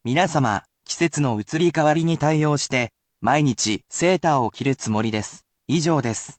I will also read this aloud, but it is usually at regular speed, and is usually very specific, so you need not repeat if it is too fast.
[polite speech]